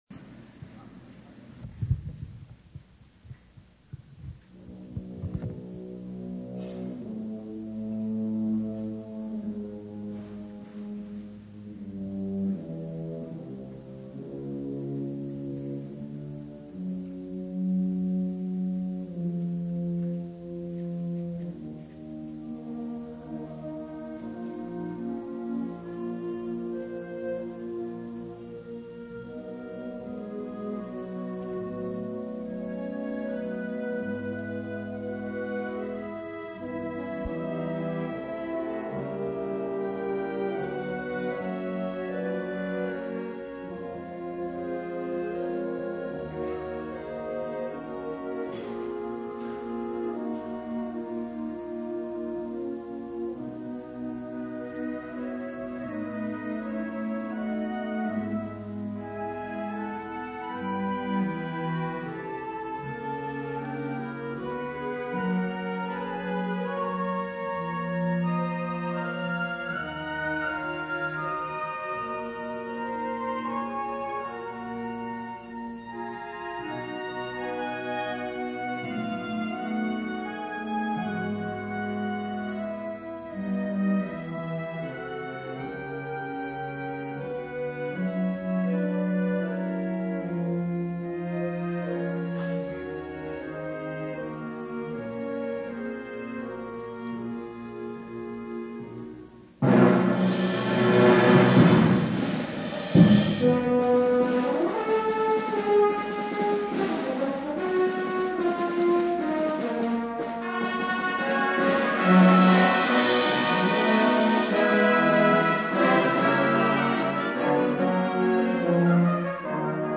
知多高吹奏楽部コンクールの記録